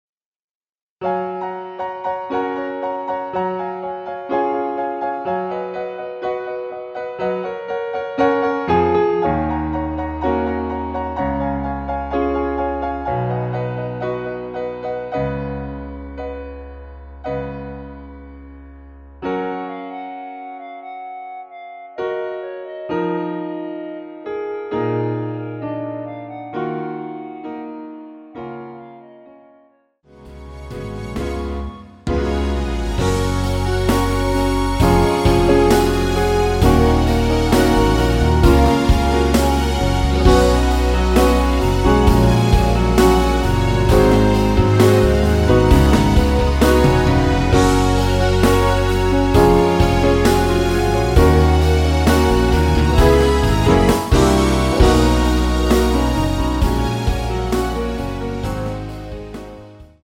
원키 멜로디 포함된 MR입니다.
F#
앞부분30초, 뒷부분30초씩 편집해서 올려 드리고 있습니다.
(멜로디 MR)은 가이드 멜로디가 포함된 MR 입니다.